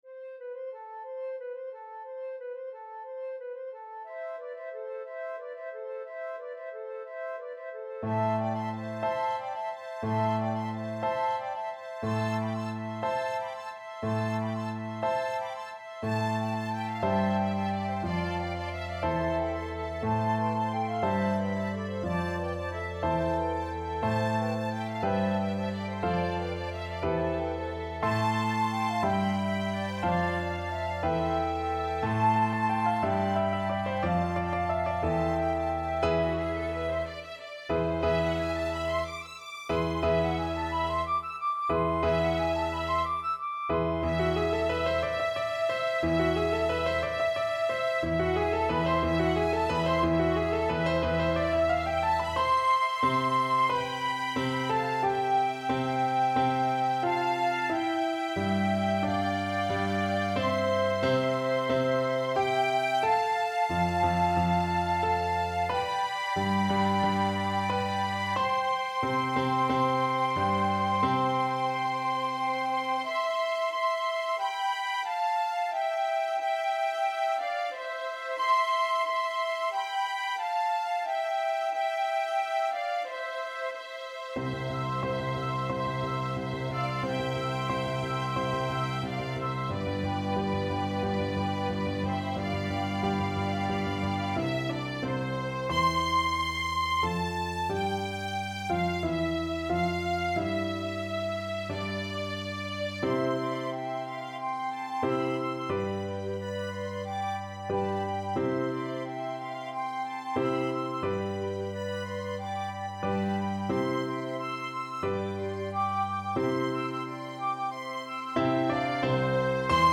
🎄 A Festive Ensemble for Flute, Violin, and Piano 🎄